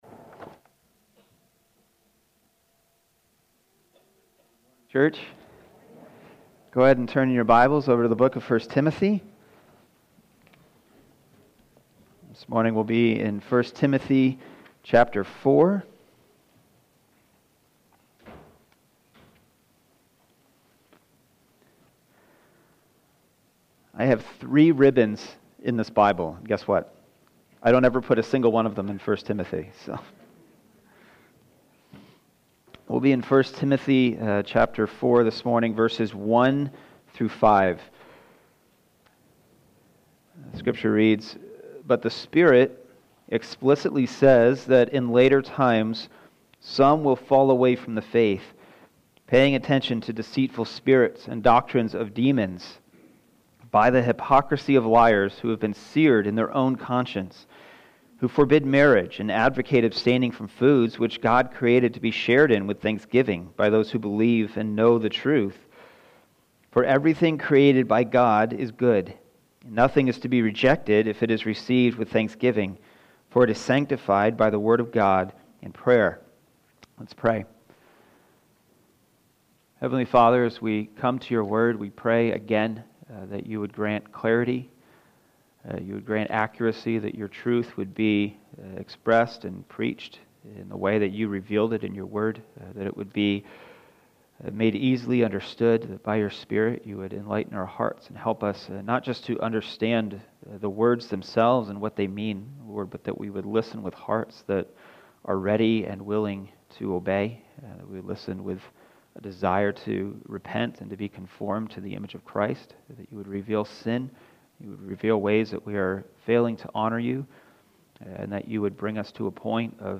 Sunday Morning - Fellowship Bible Church